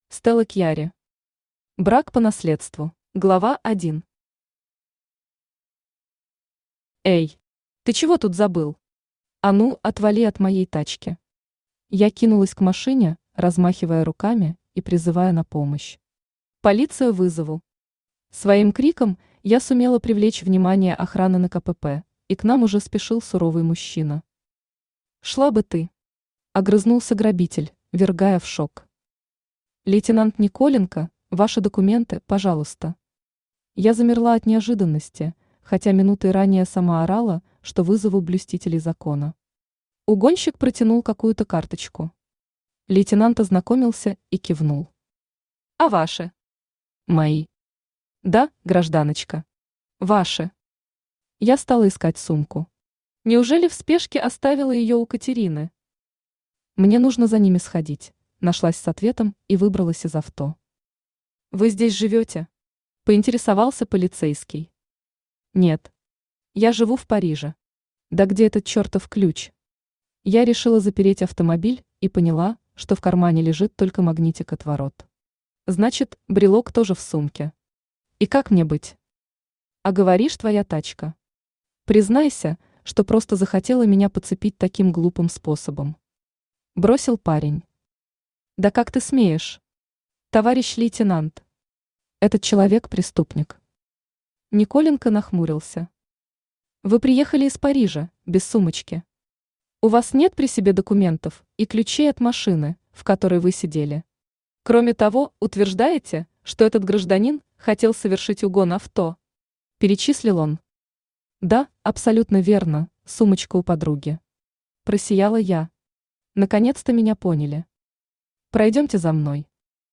Аудиокнига Брак по наследству | Библиотека аудиокниг
Aудиокнига Брак по наследству Автор Стелла Кьярри Читает аудиокнигу Авточтец ЛитРес.